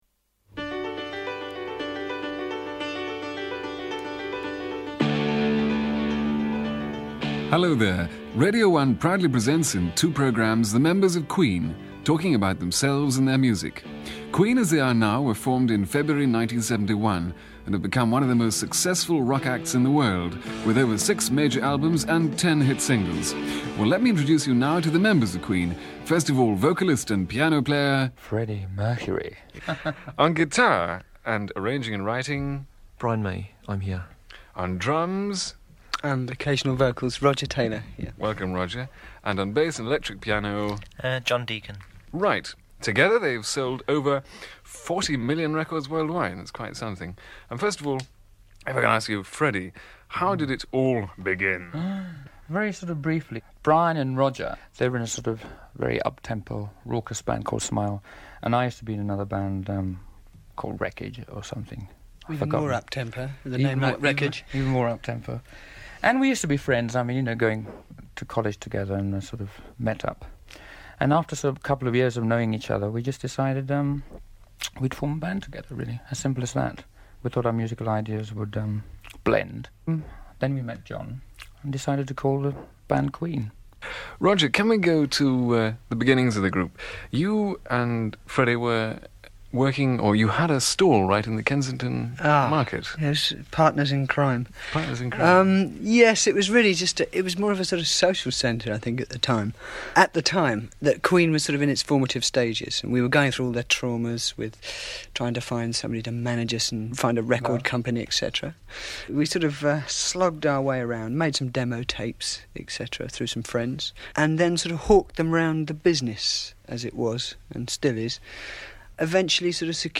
BBC Radio 1 Inteview - Christmas 1977 [QueenConcerts]